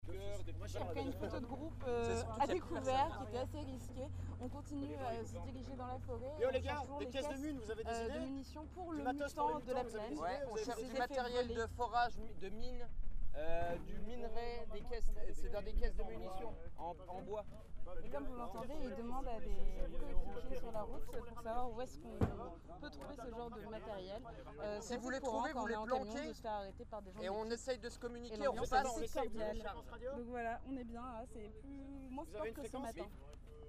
ambiance-camion.mp3